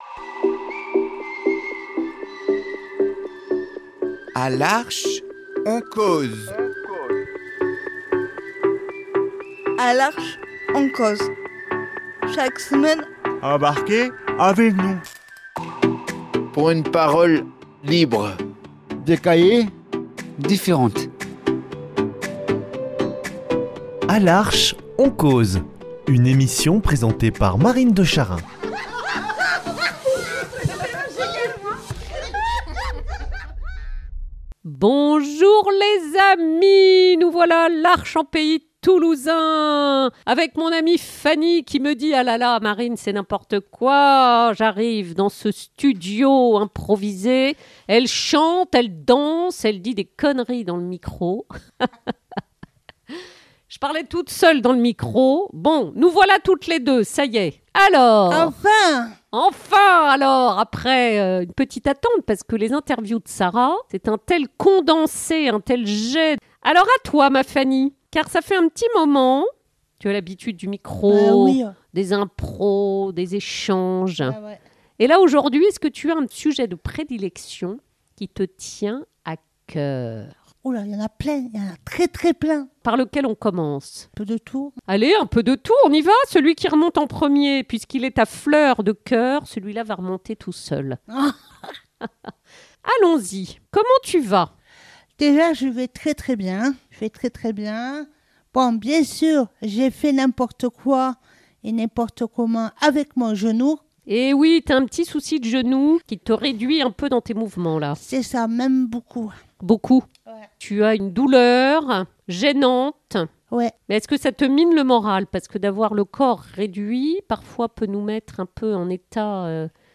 Le micro est l’occasion de confier les joies, les galères, les rêves, les besoins…C’est le but de ces interviews : offrir un espace à la parole de nos Amis !